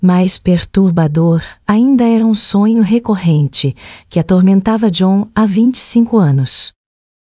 Áudios Gerados - Genesis TTS